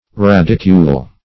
radicule - definition of radicule - synonyms, pronunciation, spelling from Free Dictionary Search Result for " radicule" : The Collaborative International Dictionary of English v.0.48: Radicule \Rad"i*cule\ (r[a^]d"[i^]*k[=u]l), n. (Bot.)
radicule.mp3